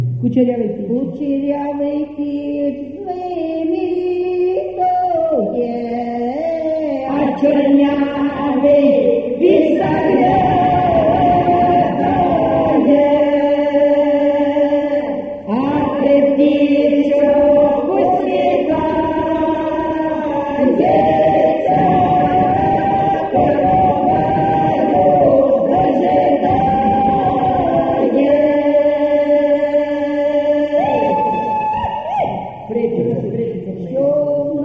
ЖанрВесільні
Місце записус. Веселий Поділ, Семенівський район, Полтавська обл., Україна, Полтавщина